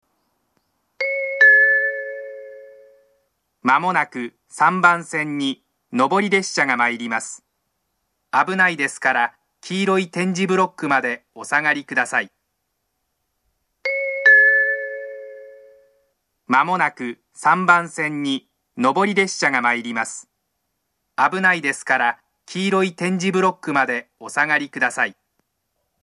接近放送の流れるタイミングは遅めで、通過列車の場合放送の最後に列車が通過してしまいます。
接近放送前のチャイムが上下で異なるのはそのままです。
３番線上り接近放送